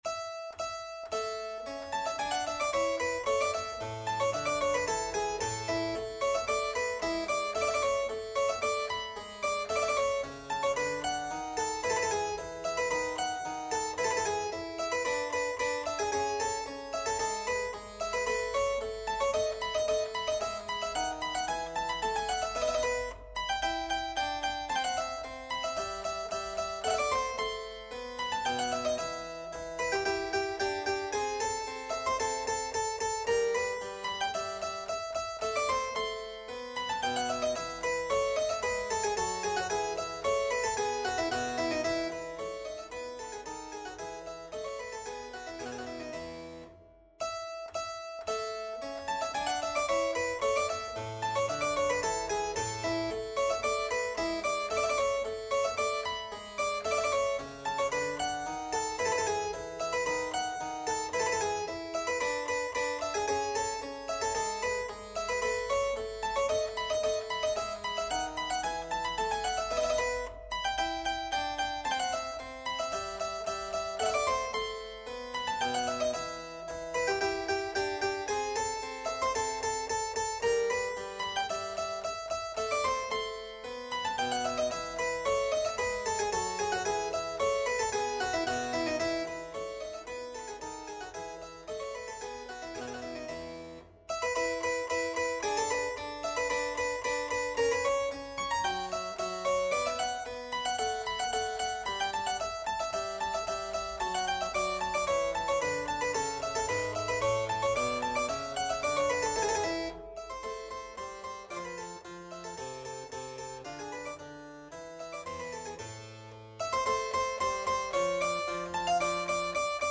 Classical Scarlatti, Domenico Keyboard Sonata in A major, K.322 Piano version
No parts available for this pieces as it is for solo piano.
Piano  (View more Easy Piano Music)
Classical (View more Classical Piano Music)